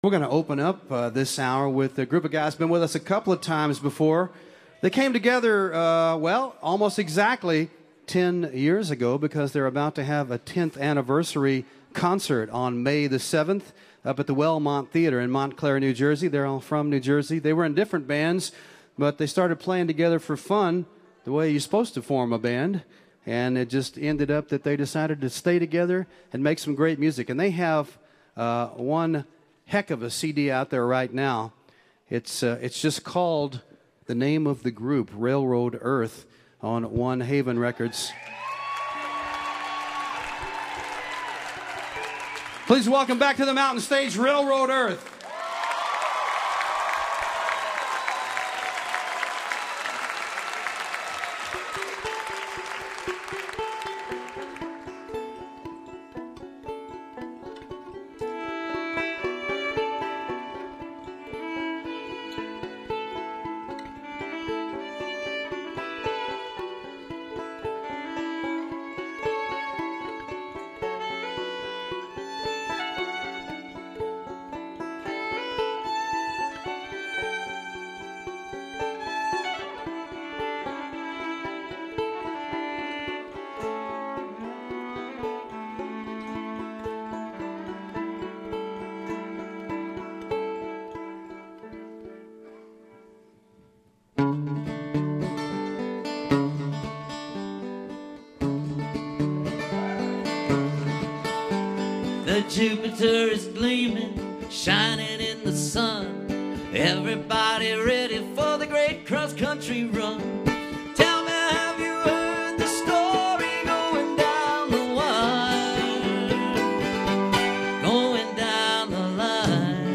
improvisational, genre-spanning music
mixes folk, country and rock with a bluegrass sensibility